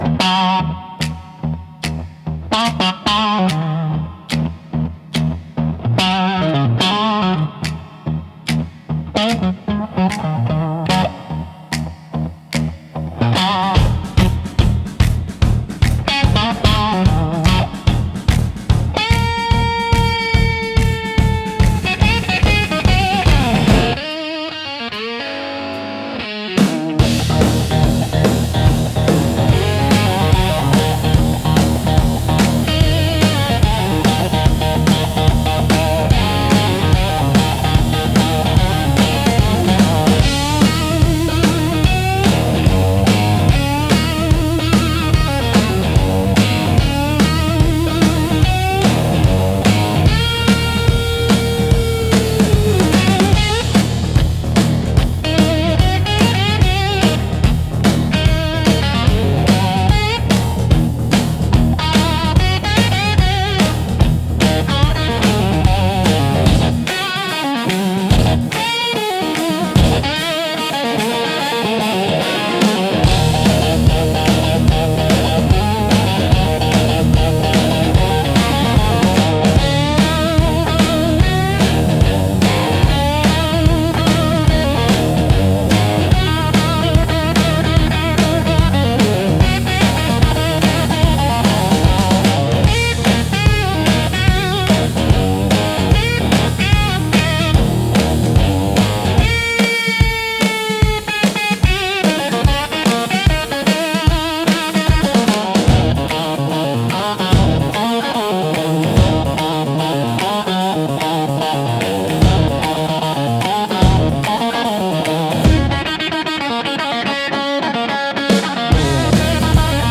Instrumental - Slide at Sundown